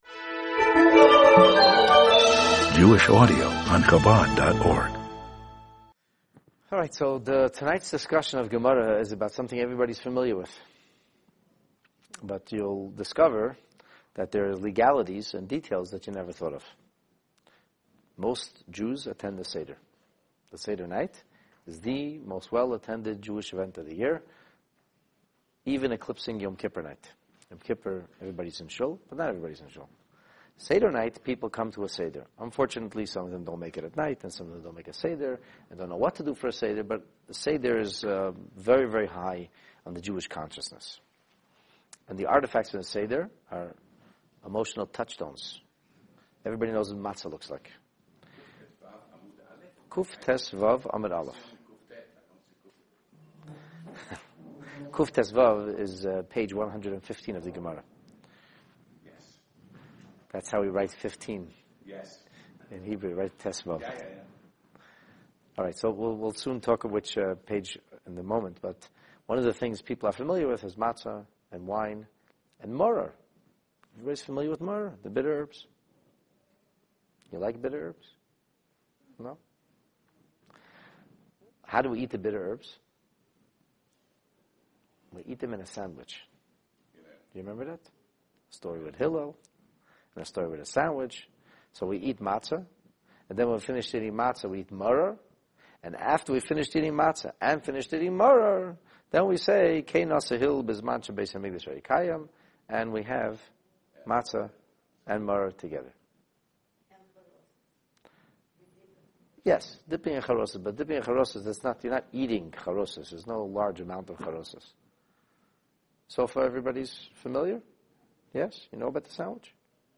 The Talmud on the Pesach Seder, Lesson 1 (Daf/Page 115a) This Talmudic offering on traces the Signature Jewish-Sandwich back to its bitter roots! See why the proverbial Hillel innovation of eating the Maror (bitter herbs) together with the Matzoh (bread of affliction) gives us a strange and unique legal challenge!